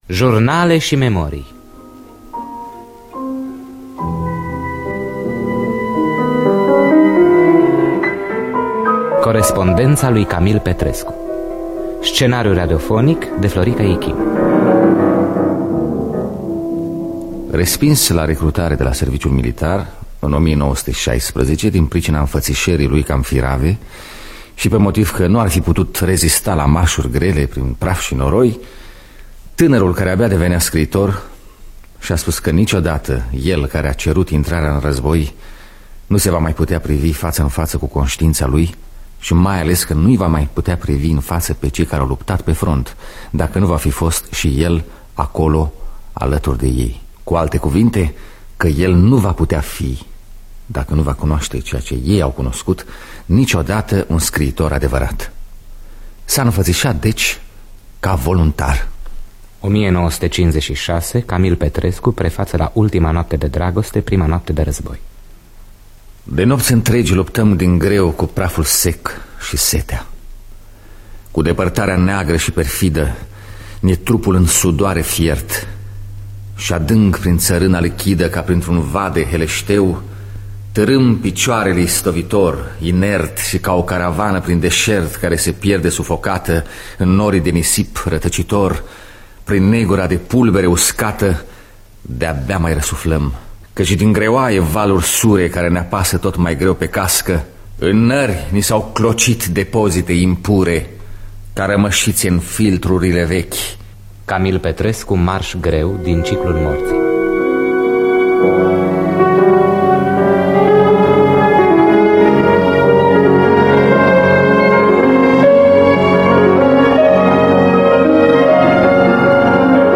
Scenariu radiofonic de Florica Ichim.